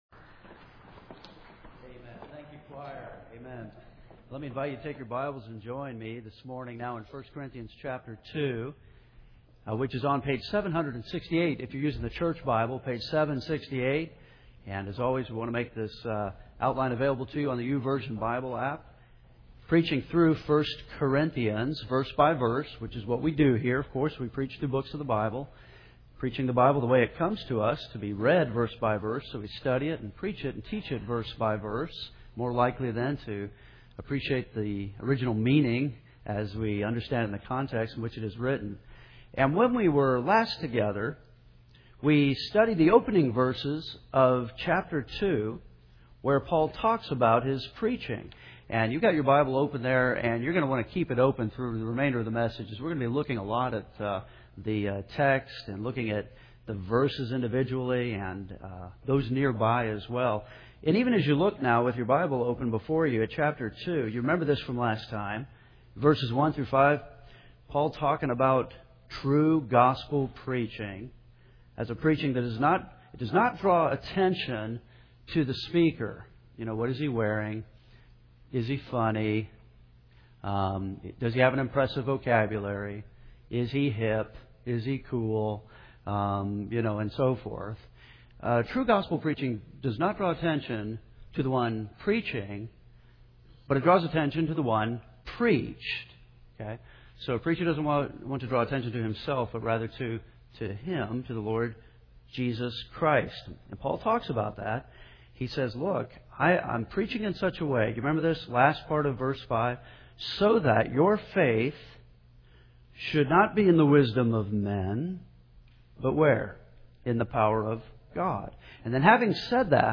We’re preaching through 1 Corinthians, verse-by-verse.